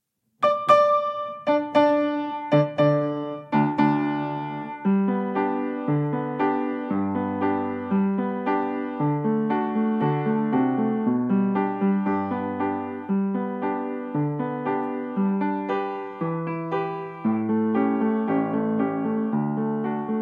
Allegro: 116 BMP
Nagranie dokonane na pianinie Yamaha P2, strój 440Hz
piano